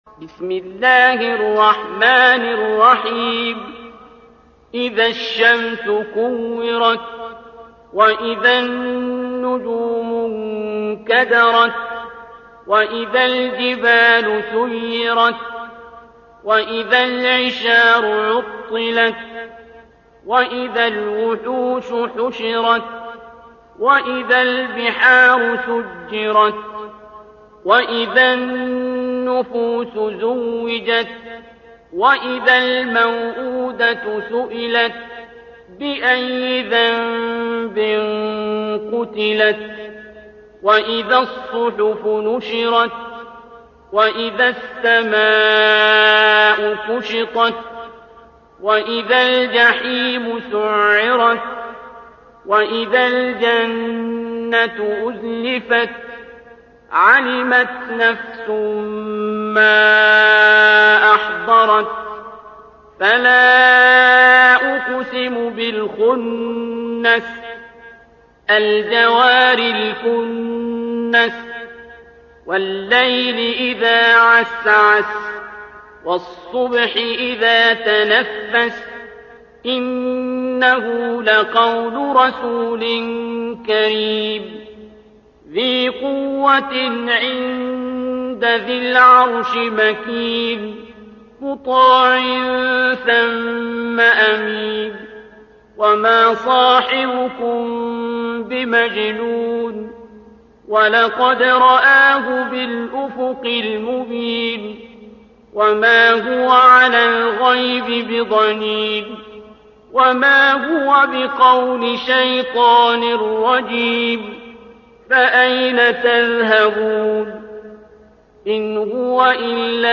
ترتيل